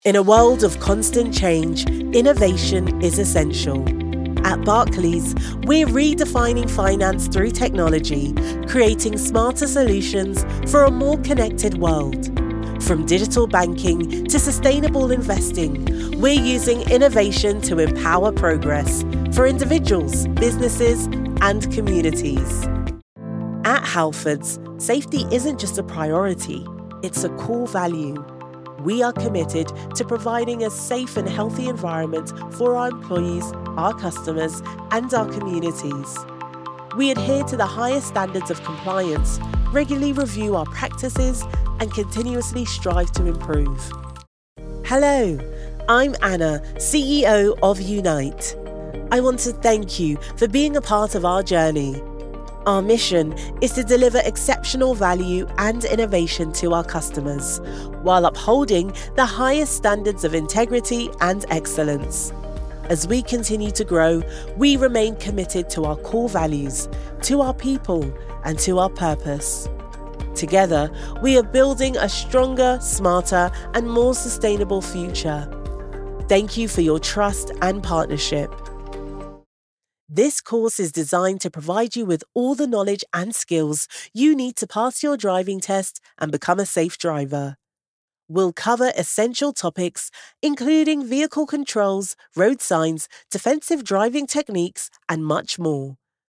Corporate Showreel
Female
Neutral British
Authoritative
Confident